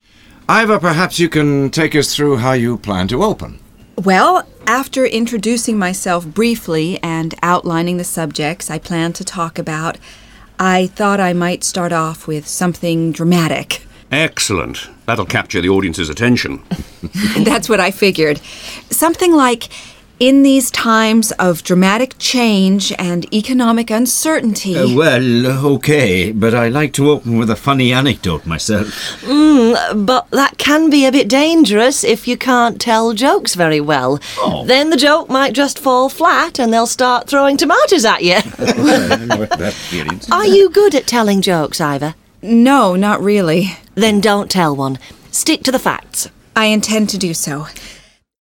Audio/Hörbuch
• Zehn hörspielartige Dialogszenen mit den wichtigsten Gesprächssituationen in Verhandlungen auf Audio-CD und als MP3-Download.
• Britisches und amerikanisches Englisch.